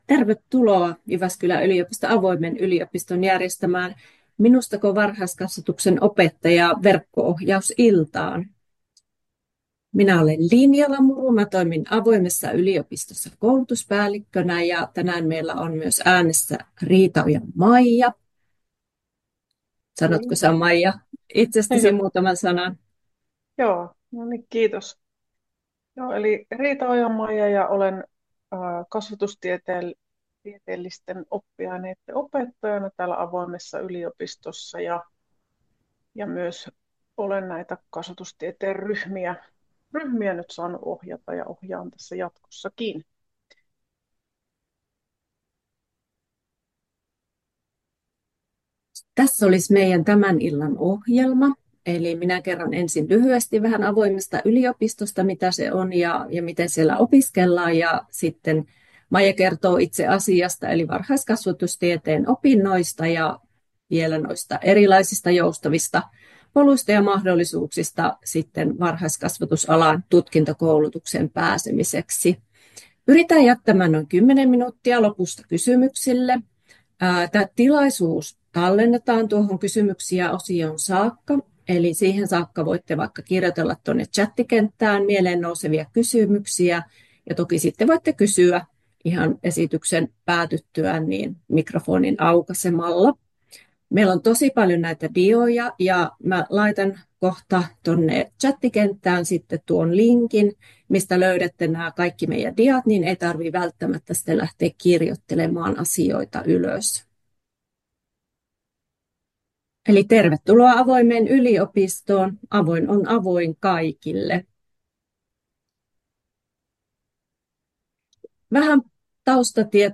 2.12.2024 pidetty verkkotilaisuuden tallenne.